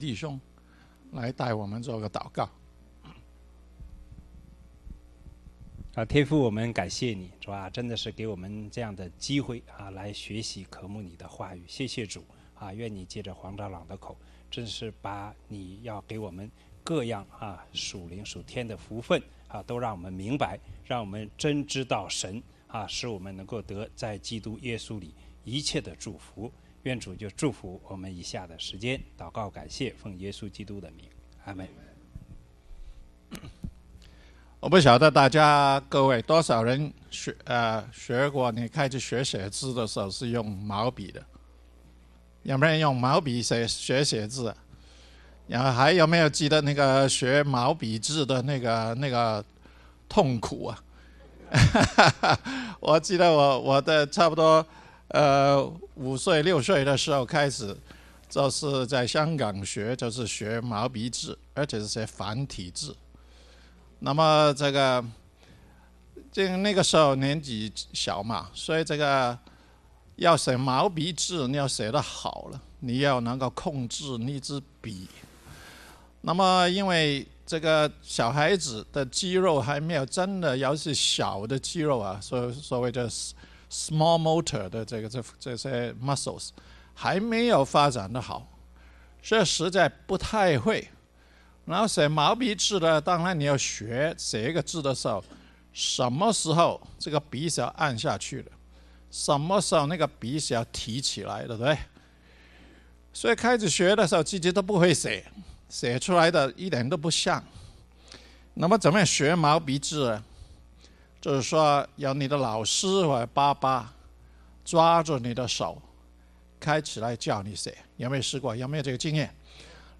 活在神的設計裡： 細讀以弗所書 – 第八講 – 奧斯汀磐石教會